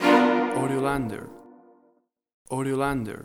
B MINOR CELLO HORROR STAB – 0:03″
A single shot of a staccato Cello in the key of B Minor.
WAV Sample Rate: 16-Bit stereo, 44.1 kHz
B-Minor-Cello-Horror-StabCS.mp3